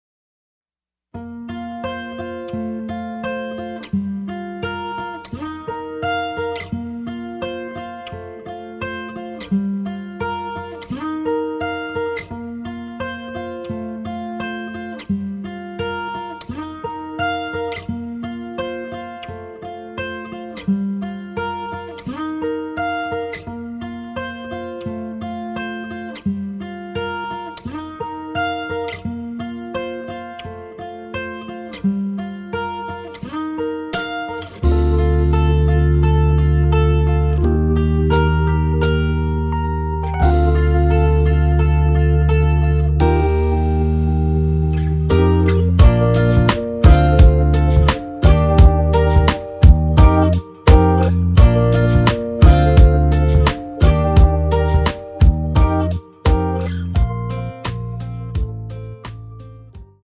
Bb
앞부분30초, 뒷부분30초씩 편집해서 올려 드리고 있습니다.
중간에 음이 끈어지고 다시 나오는 이유는